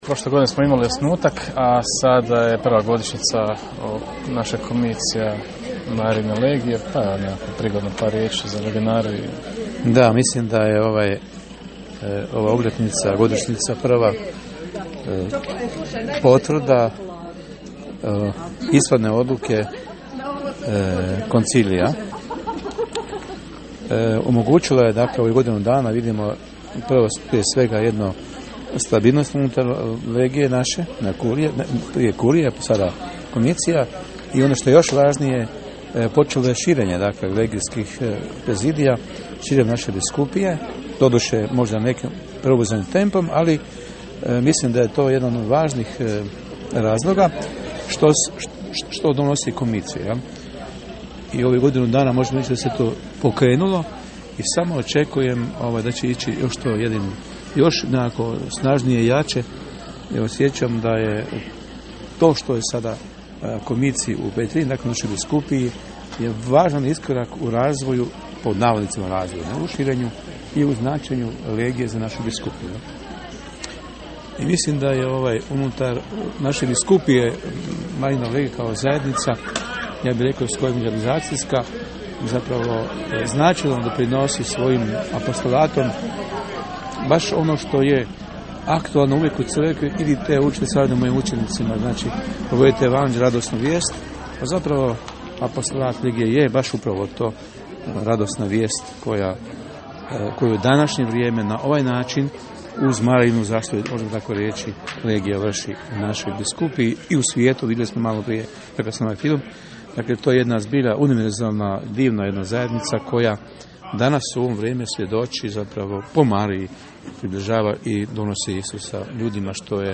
Nakon misnog slavlja smo porazgovarali s njim te je poru?io legionarima: